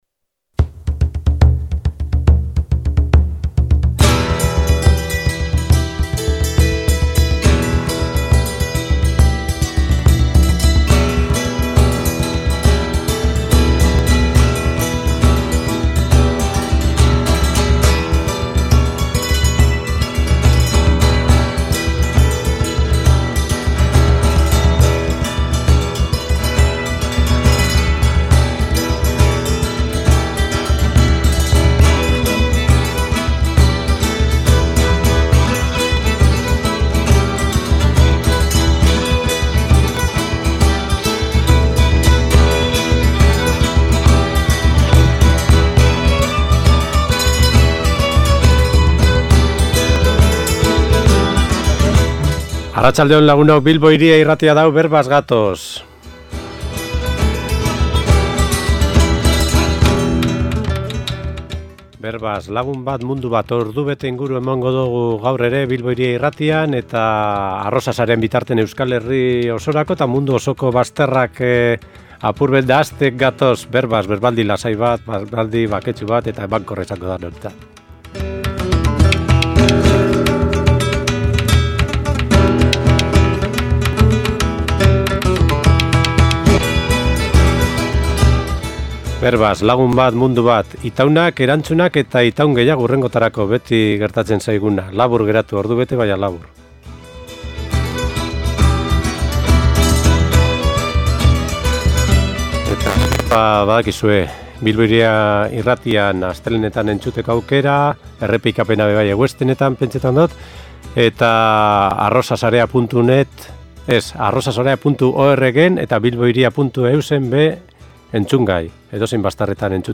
Elkarrizketa oso polito bukatu dugu